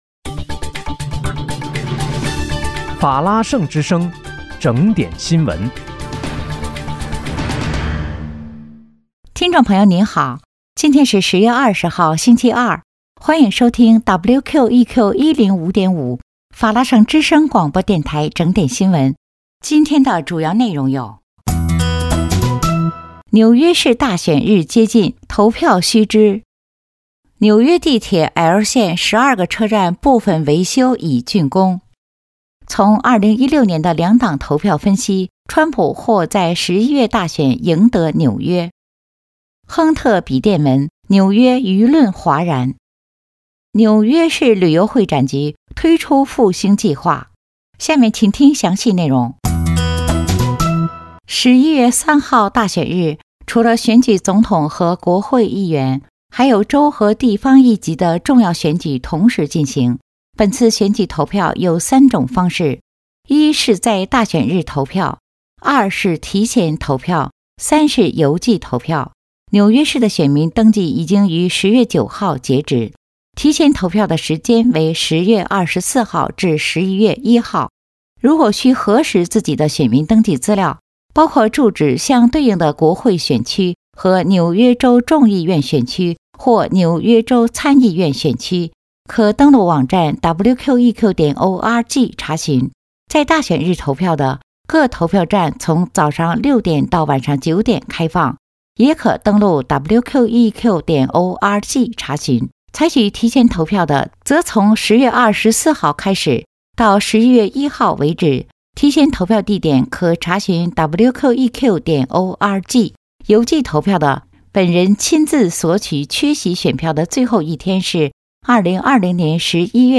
10月20日（星期二）纽约整点新闻